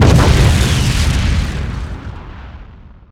explode2.ogg